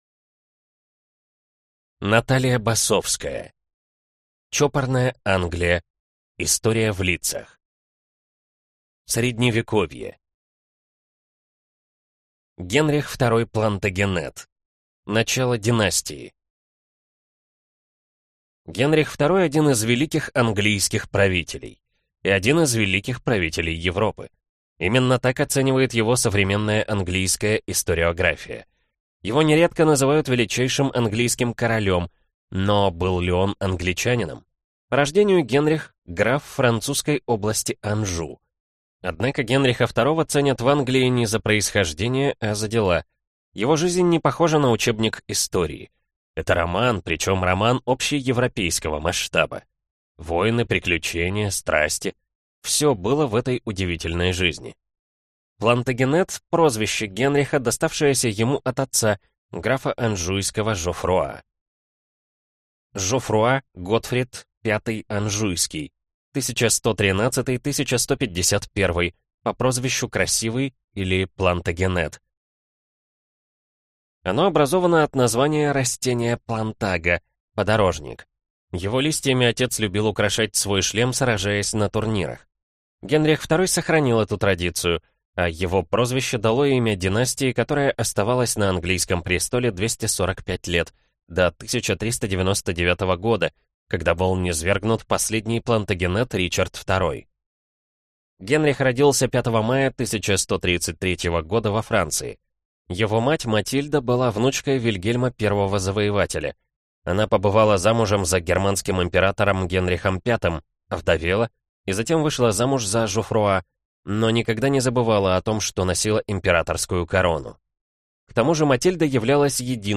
Аудиокнига Чопорная Англия. История в лицах (полная версия) | Библиотека аудиокниг